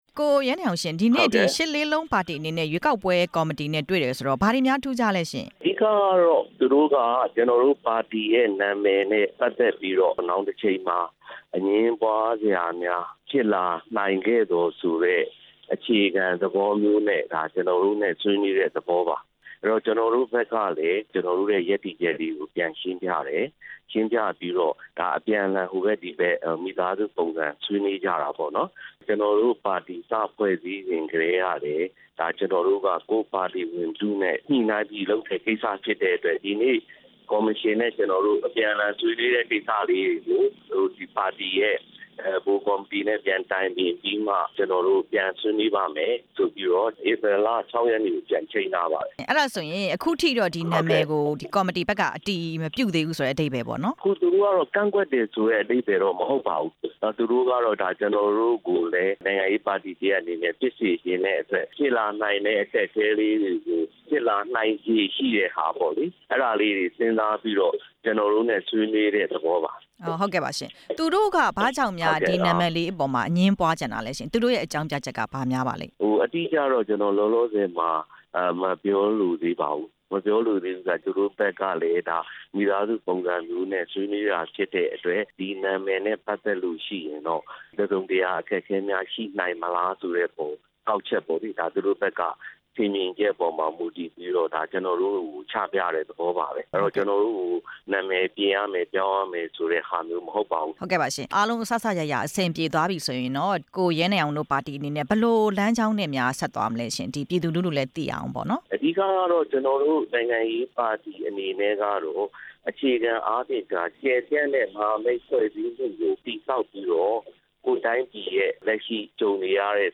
ရှစ်လေးလုံးပါတီရဲ့ ဘုံမဟာရည်မှန်းချက်၊ ဦးဆောင်သူတစ်ဦးနဲ့ မေးမြန်းချက်